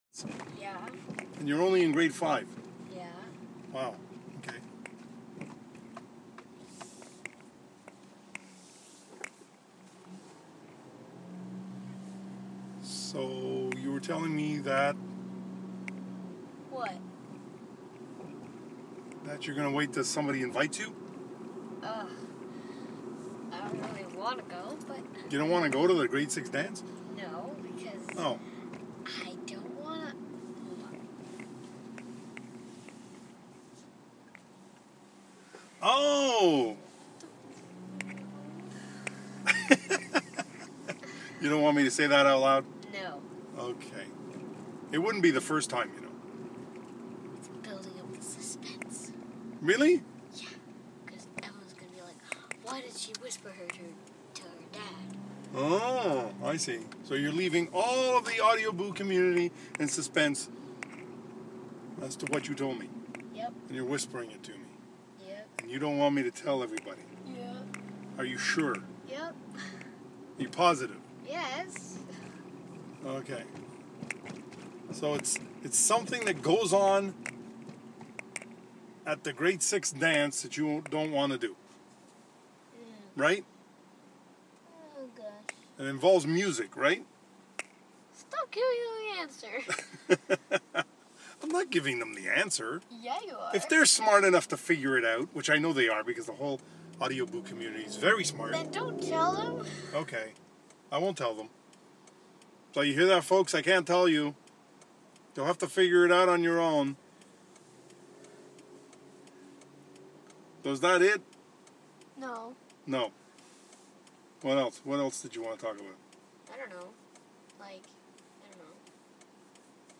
I was able to get rid of a bunch of noise at the start of this boo.